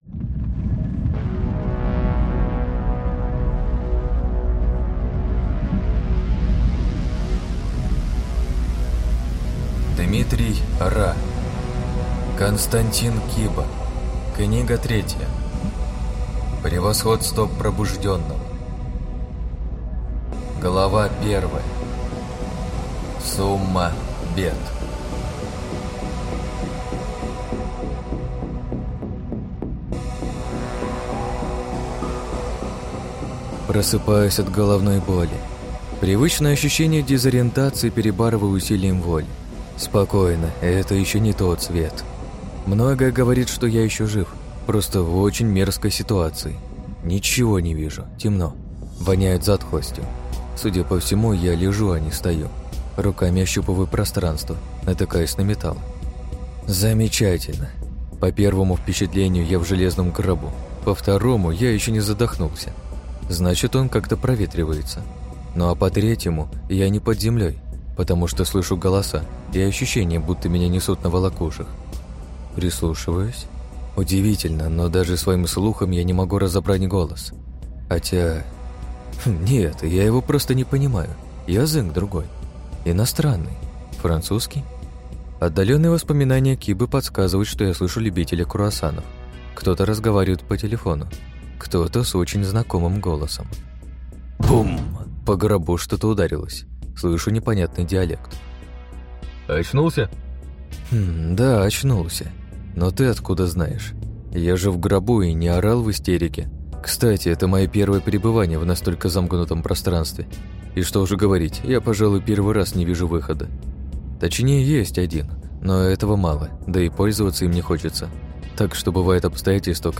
Аудиокнига Превосходство Пробужденного. Том 3 | Библиотека аудиокниг